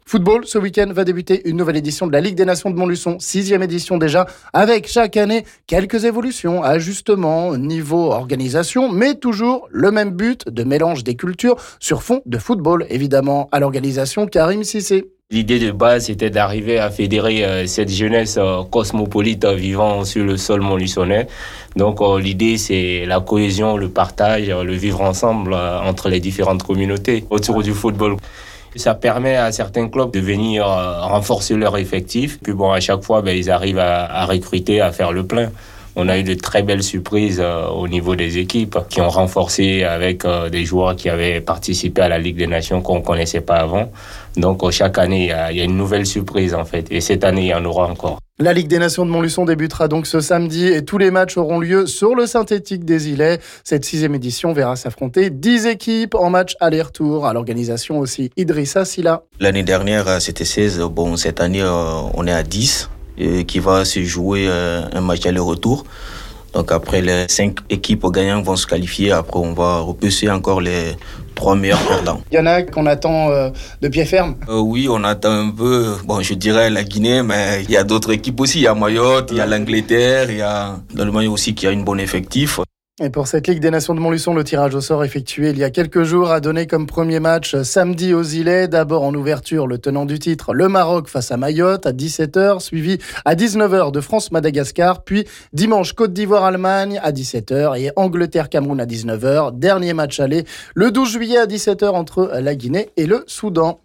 On en parle ici avec les organisateurs